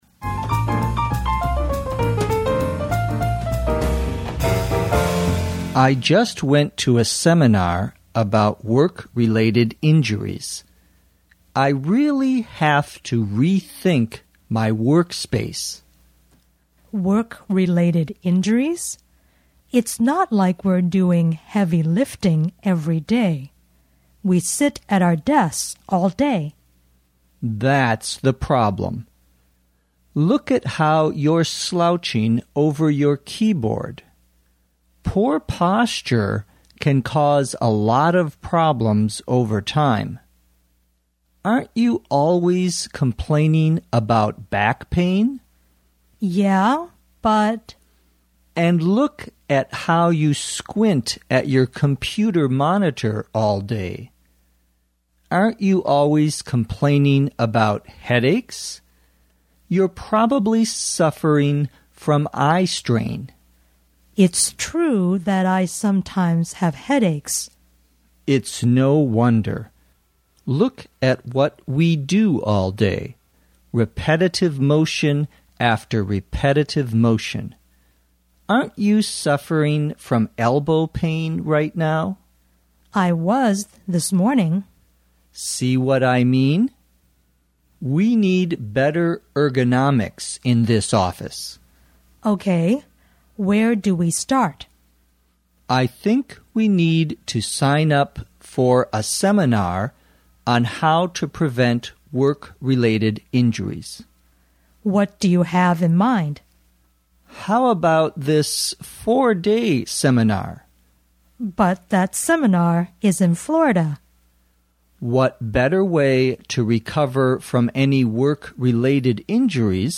地道美语听力练习:因工负伤